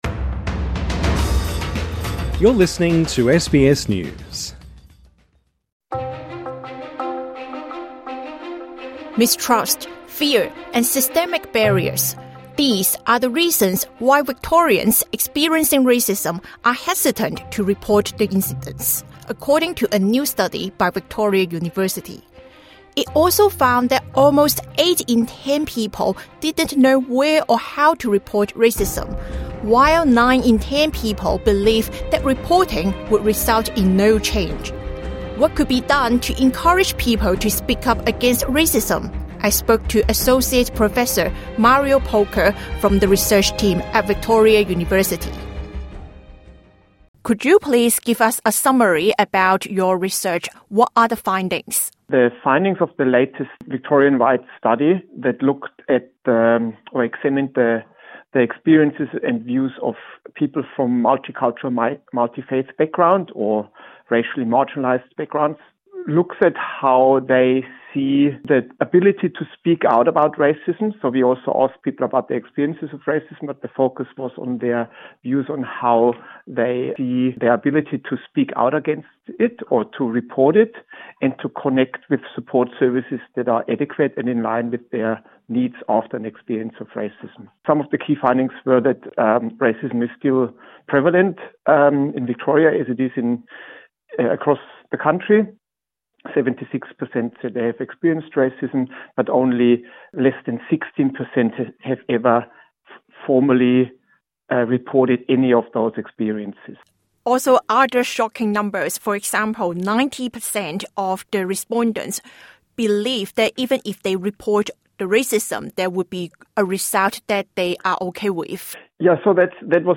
INTERVIEW: Why don't people speak up against racism?